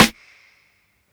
Snares
VLICK_SNR.wav